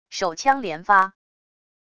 手枪连发wav音频